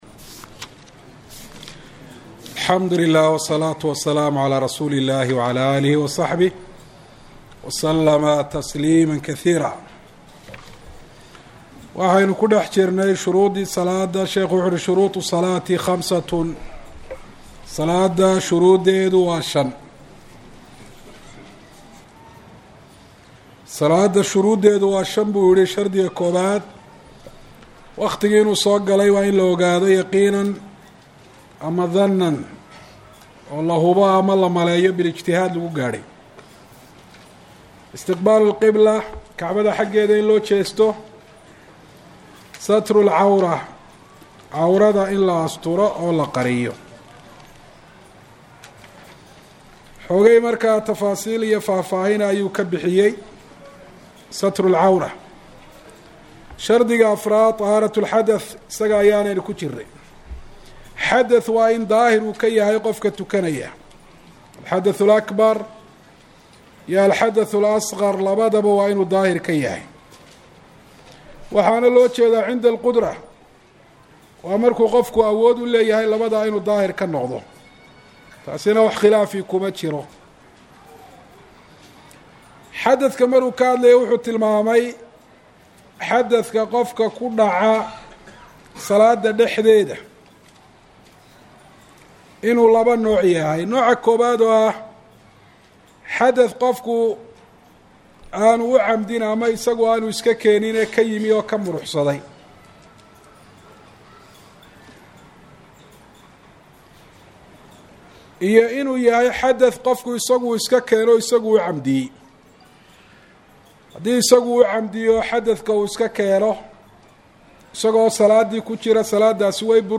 Masjid Nakhiil – Hargaisa